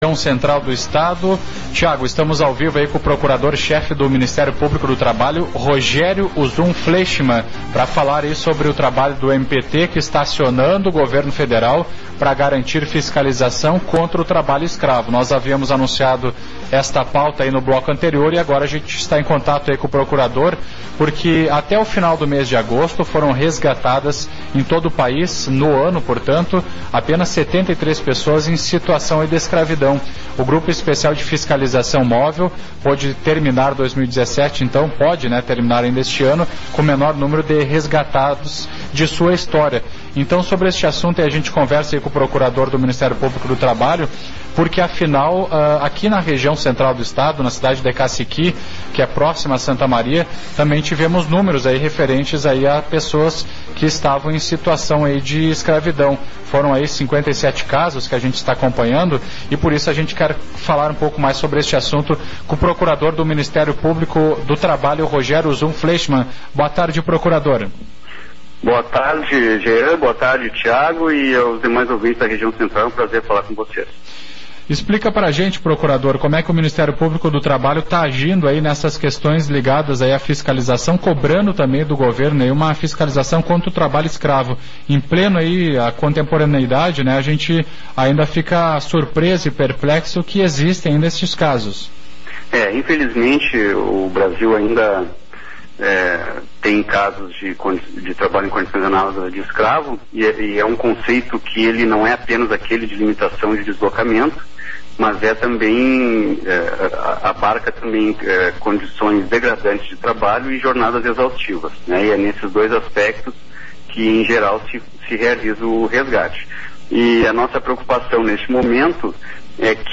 Entrevista com o procurador-chefe do Minist�rio P�blico do Trabalho no Rio Grande do Sul (MPT-RS), Rog�rio Uzun Fleischmann